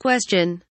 question kelimesinin anlamı, resimli anlatımı ve sesli okunuşu